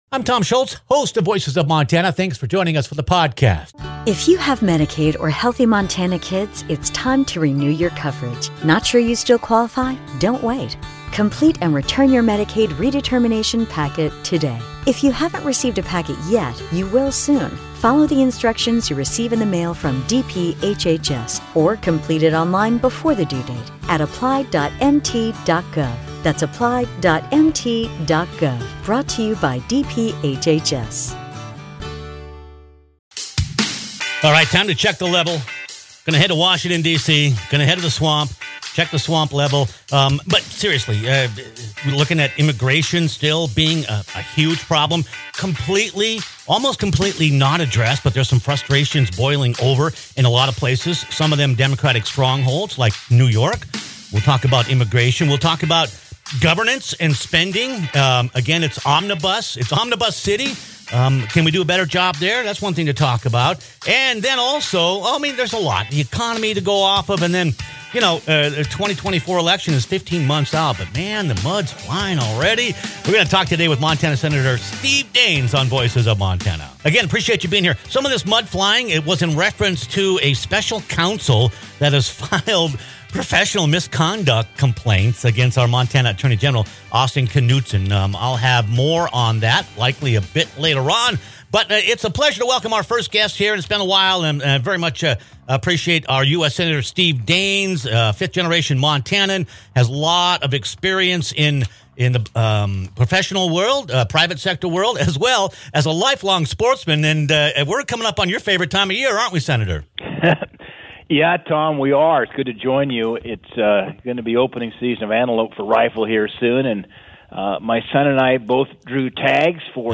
Montana US Senator Steve Daines talks with Voices of Montana callers and discusses his concerns with the country’s direction.